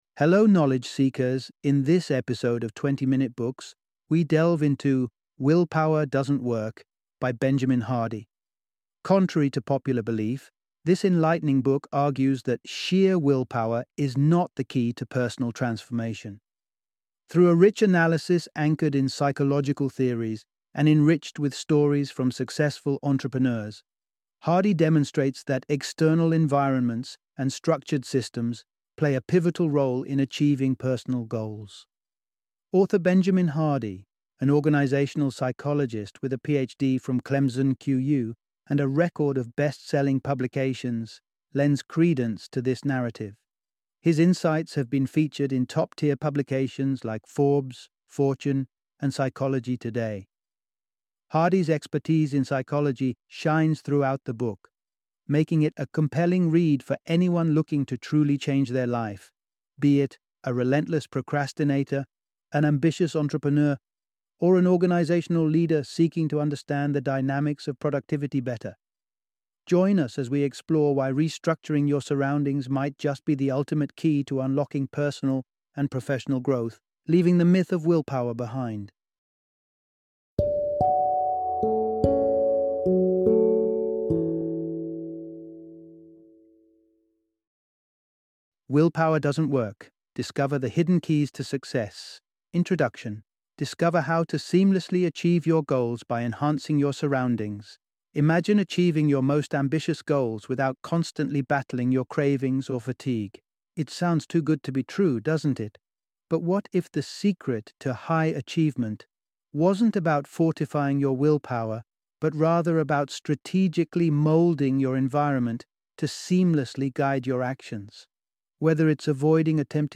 Willpower Doesn't Work - Audiobook Summary